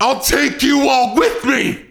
OC Psycho Voice over
Voice over intended for a suicide unit.